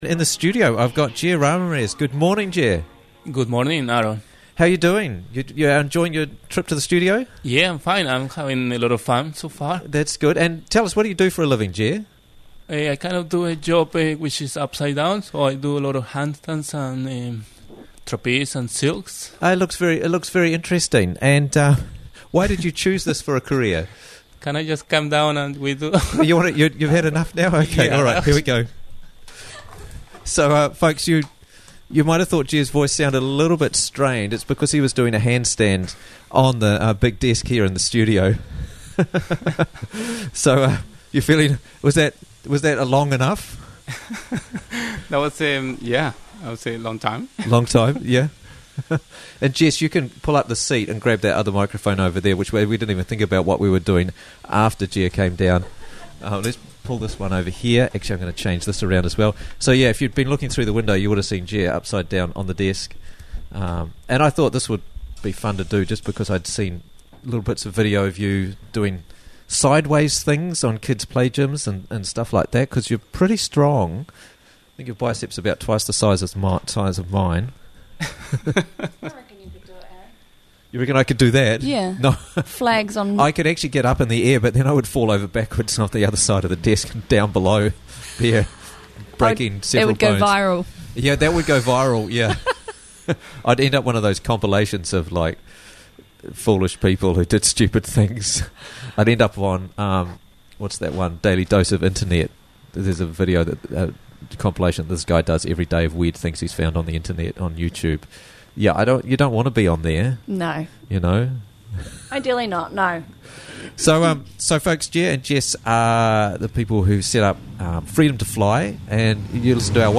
Freedom2Fly Festival 3rd November - Interviews from the Raglan Morning Show